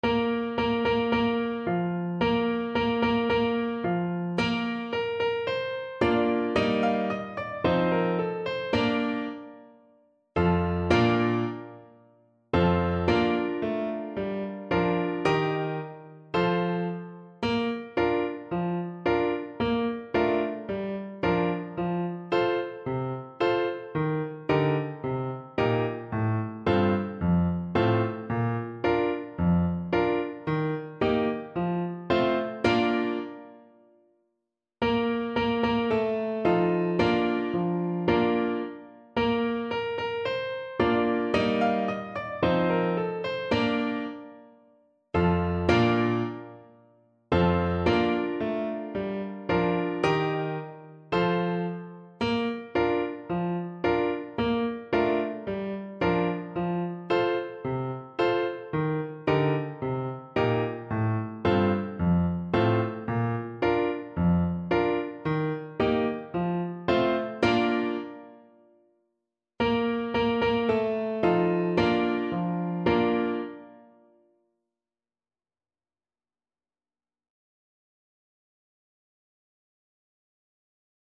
Free Sheet music for French Horn
Play (or use space bar on your keyboard) Pause Music Playalong - Piano Accompaniment Playalong Band Accompaniment not yet available transpose reset tempo print settings full screen
2/2 (View more 2/2 Music)
Bb major (Sounding Pitch) F major (French Horn in F) (View more Bb major Music for French Horn )
Two in a bar =c.80
Traditional (View more Traditional French Horn Music)
world (View more world French Horn Music)